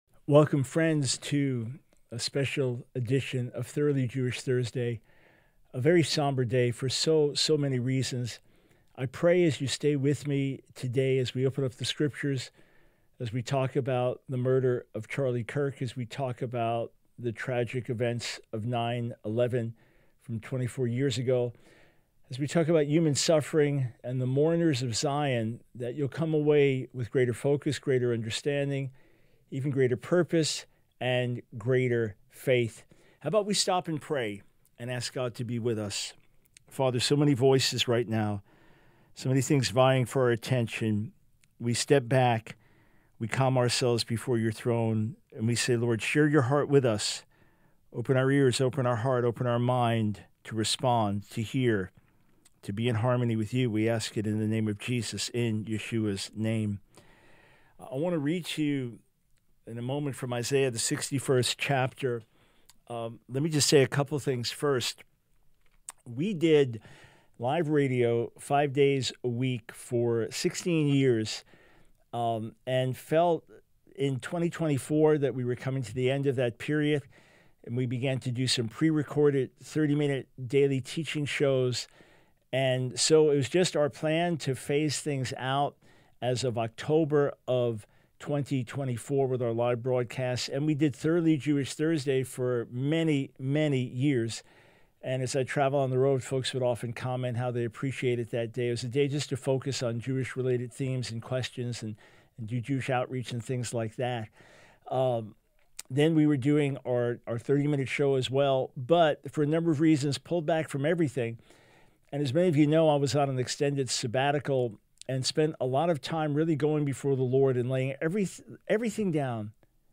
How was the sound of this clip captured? Radio Broadcast from The Line of Fire